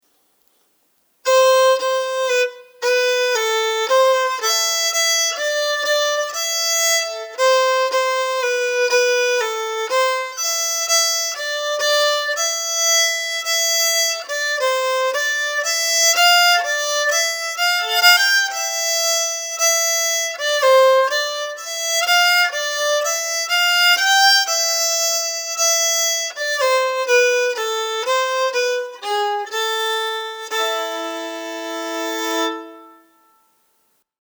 Polska efter Carl Herman Erlandsson.
Grundmelodi långsam: Download